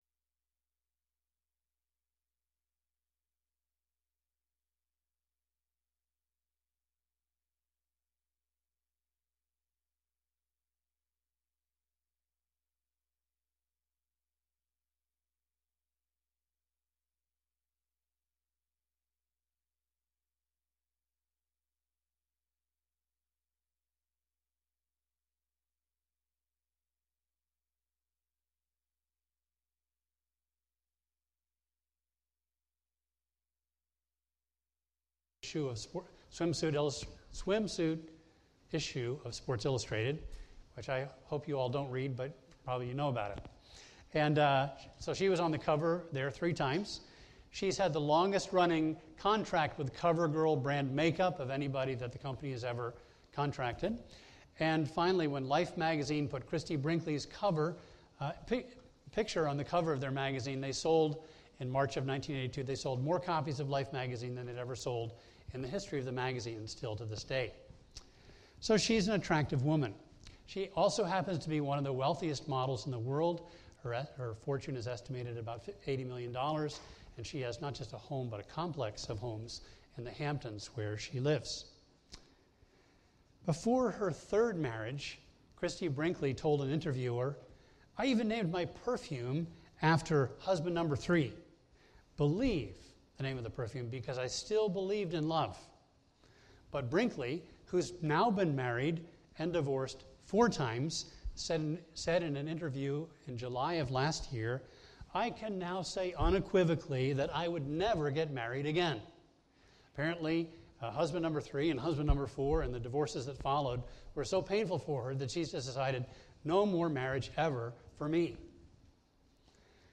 A message from the series "The Meaning of Marriage."